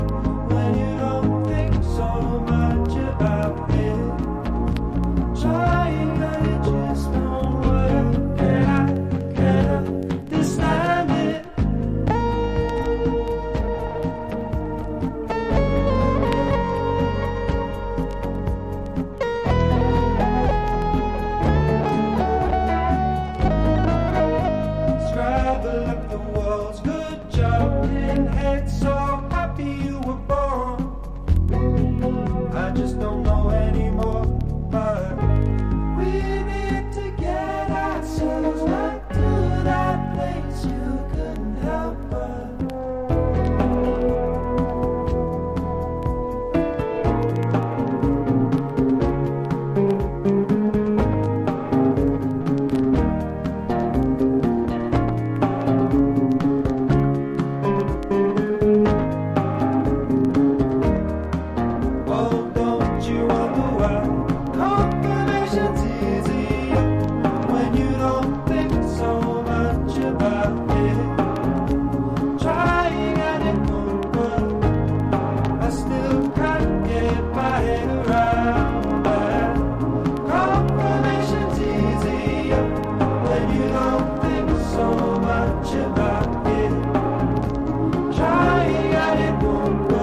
# SSW / FOLK (90-20’s)